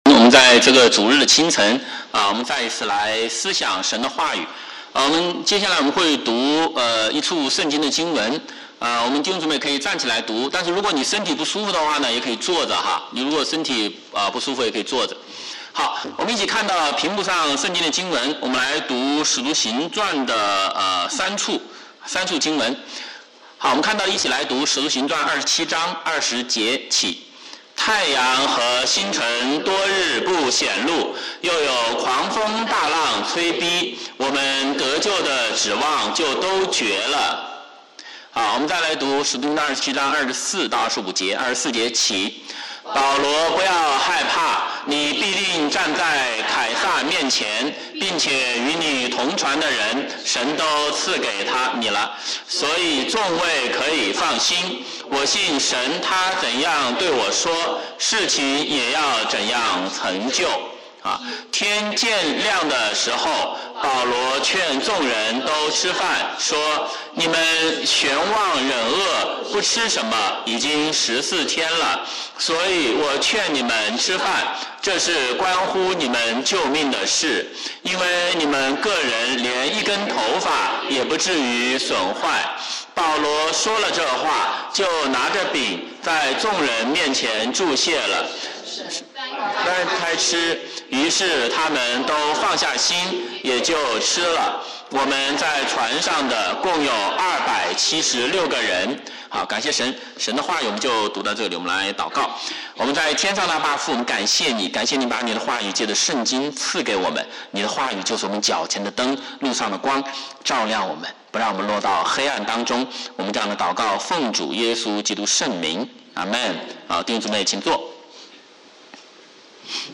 BCCC Sermon